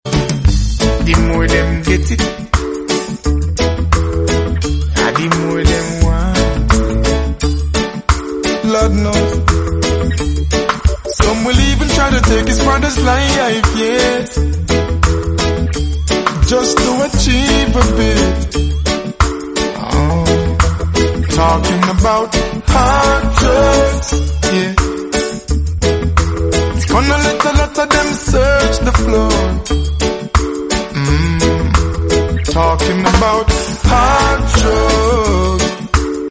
Sony Soundbar HT-S700RF 1000 watts with 2 tall boy speakers